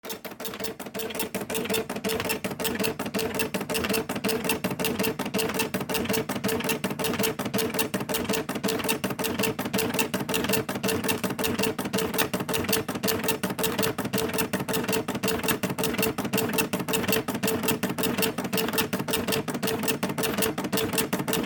Dot matrix printer being dot matrix printer